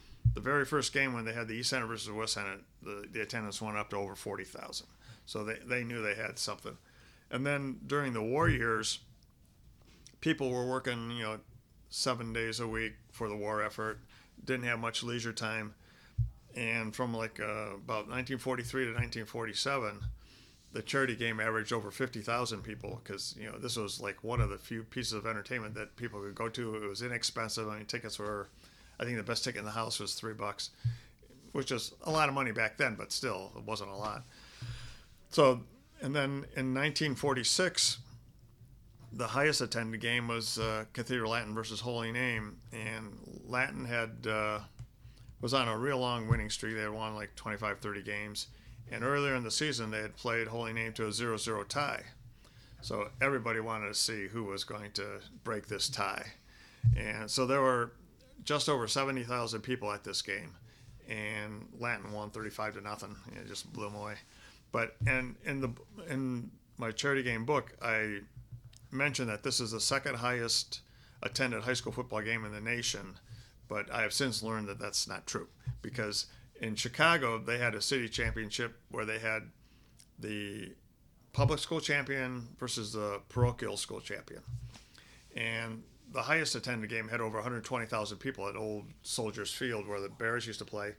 Cleveland Regional Oral History Collection.